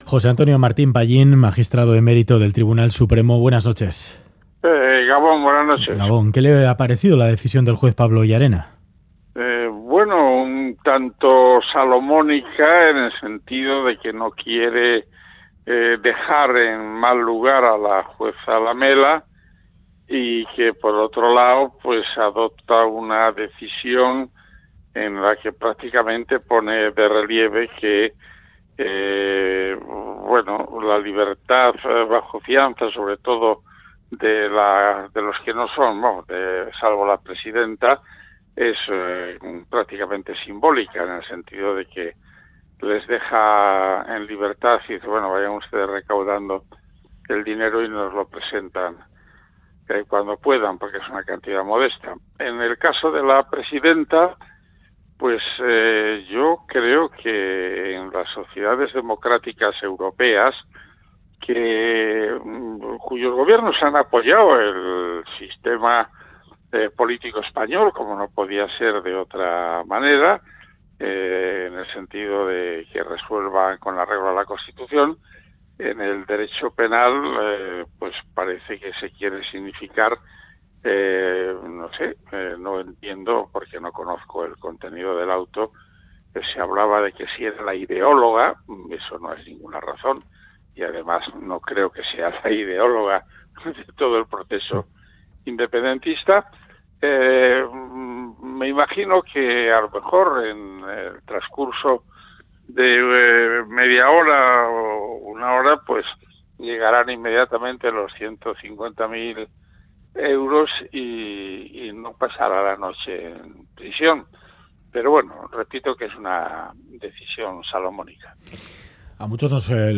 Audio: Entrevista en el informativo Ganbara de Radio Euskadi y Radio Vitoria a José Antonio Martín Pallín, fiscal del Tribunal Supremo y magistrado emérito del mismo.